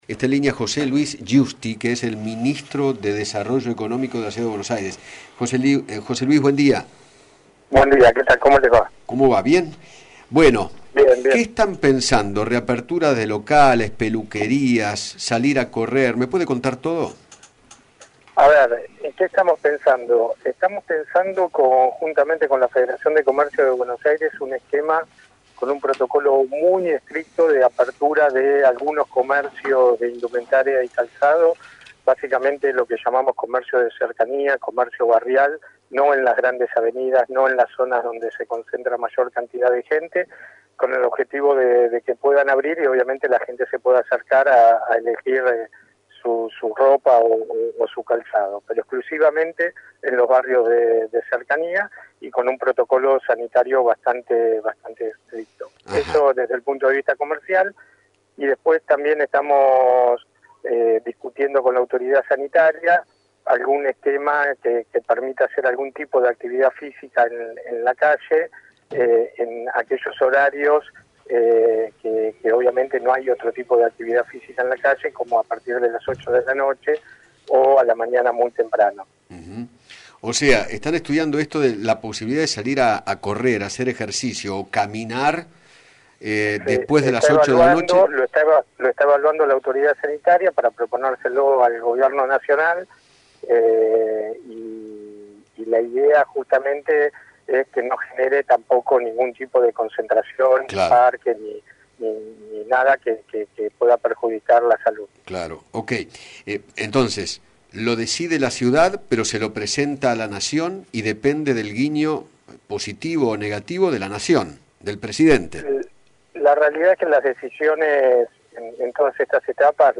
José Luis Giusti, Ministro de Desarrollo Económico de la Ciudad de Buenos Aires, dialogó con Eduardo Feinmann sobre la posibilidad de que reabran los comercios de ropa y calzado que sean de cercanía. Además, contó que otro de los temas que están discutiendo es “de qué manera realizar actividad deportiva en la calle sin que se produzca aglomeración”.